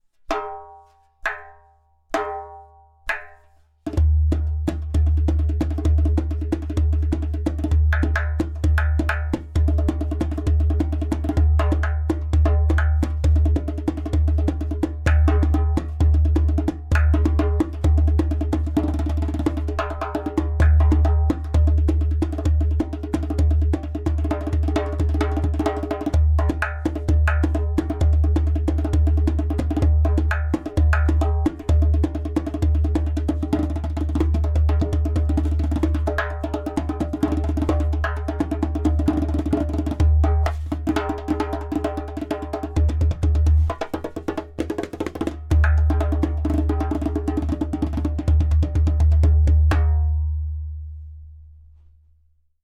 • Strong and super easy to produce clay kik (click) sound
• Beautiful harmonic overtones.
• Medium thickness goat skin (~0.3mm)
• Body: Ceramic / Clay